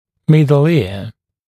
[‘mɪdl ɪə] [‘мидл иа] среднее ухо